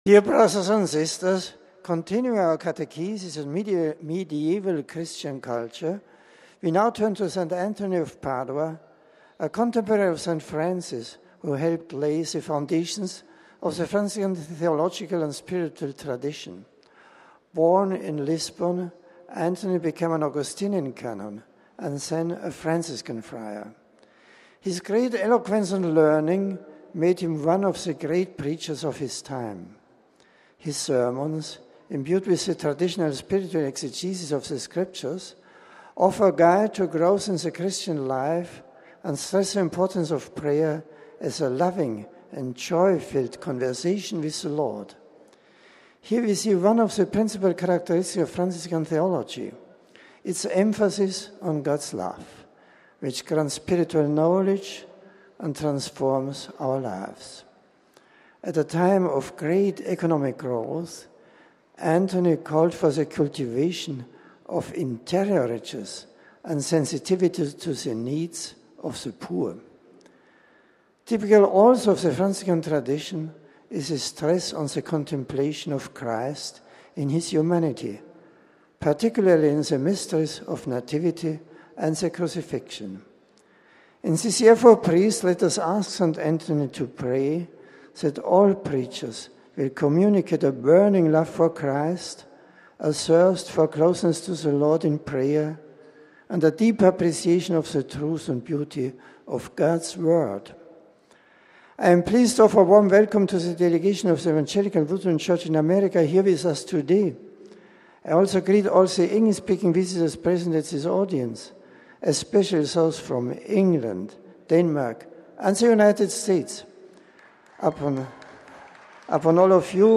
Pope Benedict XVI in English - Weekly General Audience